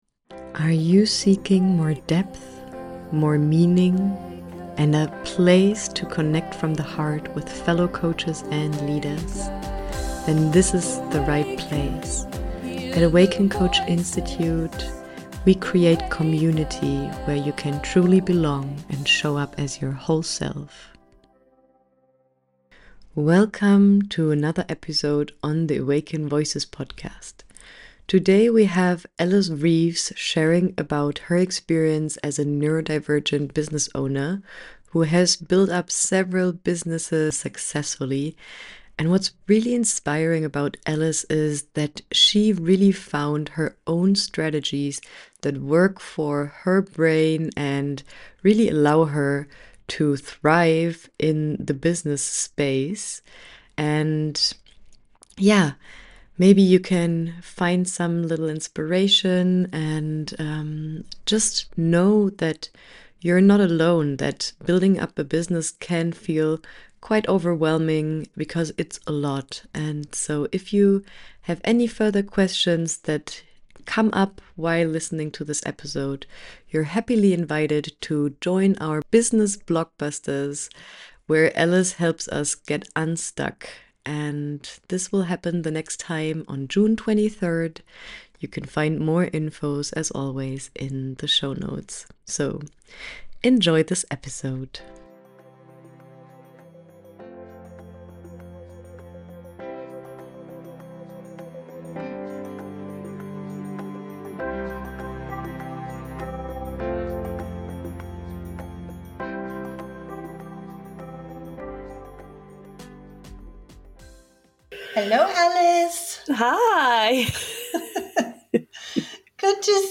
This is a powerful, practical, and affirming conversation for any coach or business owner who’s ever felt like the usual rules just don’t apply.